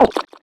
gulp.wav